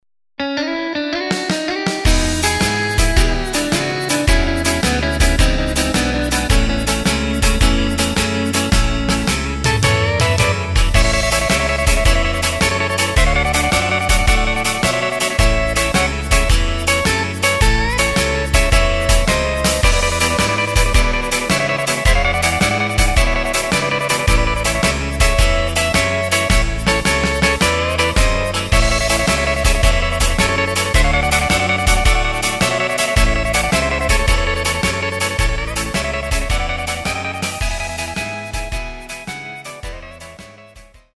Takt:          4/4
Tempo:         108.00
Tonart:            A
Guitar Solo aus dem Jahr 2020!
Midi Demo XG